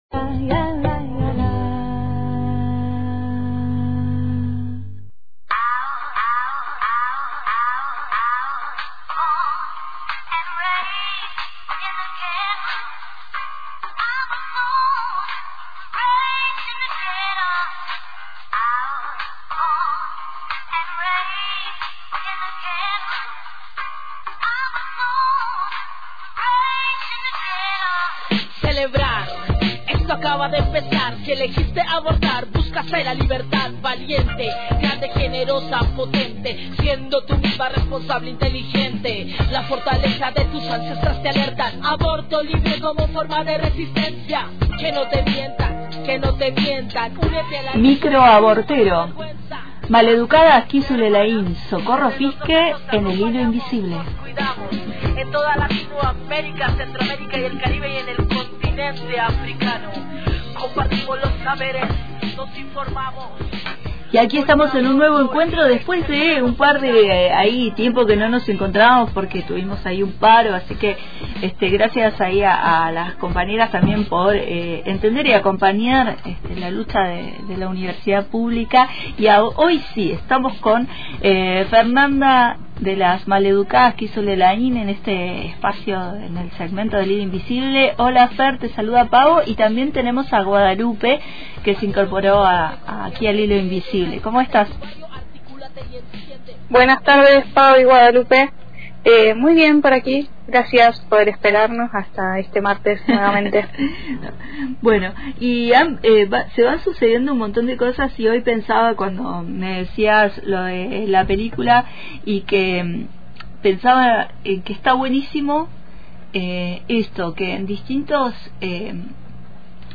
Las participantes destacaron que Belén no solo conmueve, sino que rescata estrategias de organización y resistencia que marcaron el camino hacia la sanción de la Ley 27.610. Además, subrayaron la importancia de proyectar la película en espacios abiertos y comunitarios, como el Instituto de Formación Docente de Fiske, para promover el encuentro y el debate colectivo.